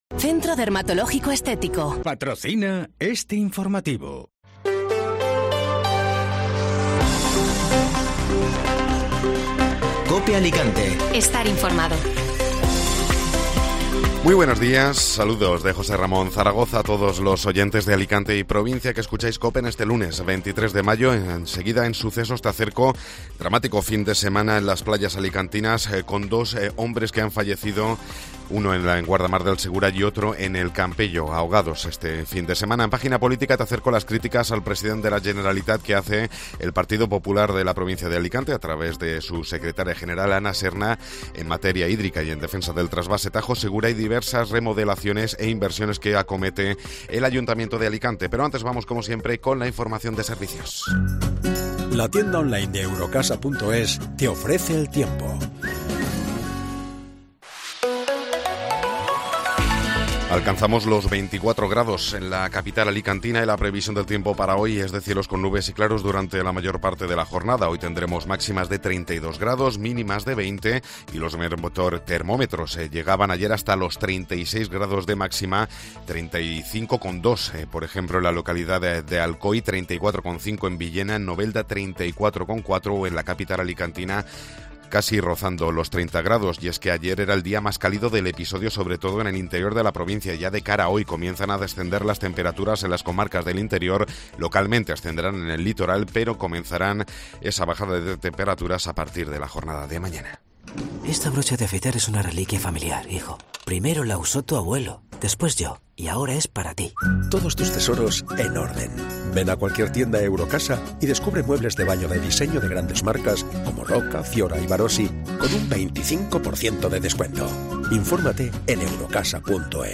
Informativo Matinal (Lunes 23 de Mayo)